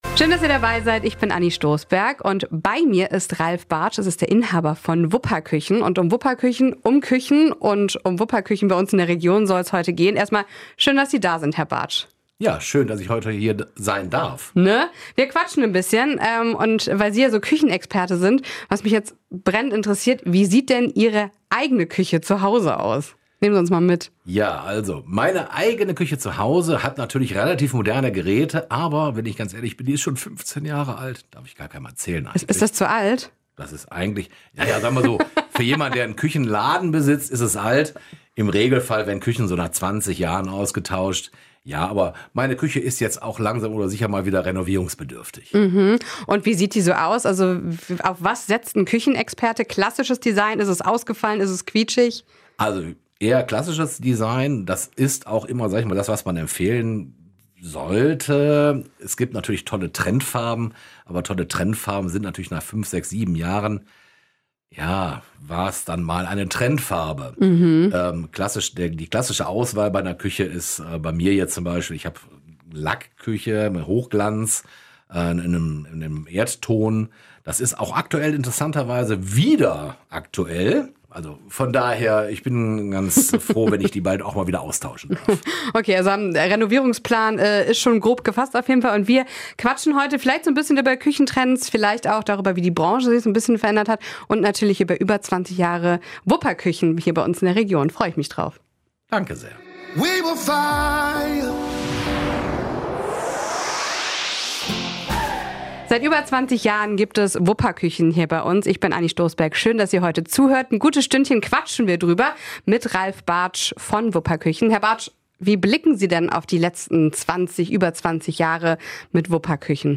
Sondersendung Wupper Küchen